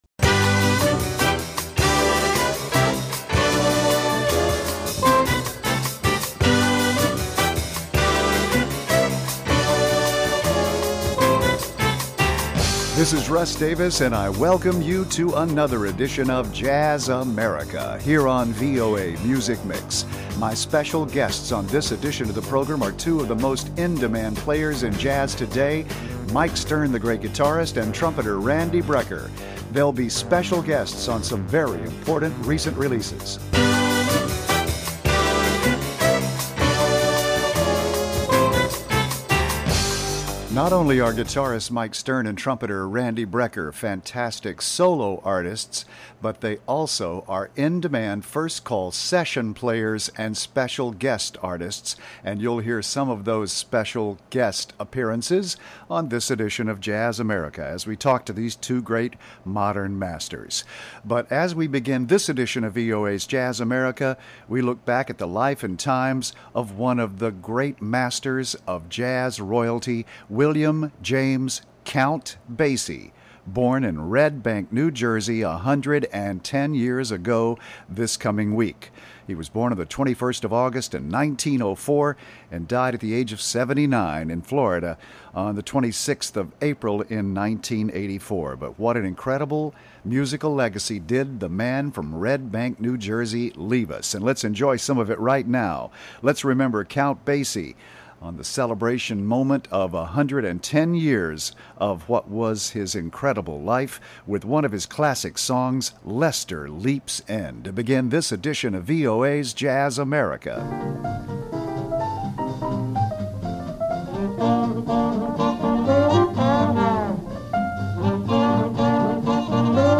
brings you the best in jazz, present and past.
interviews a musician and features music from their latest recordings.